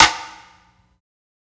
ILLMD012_SNARE_MILLI.wav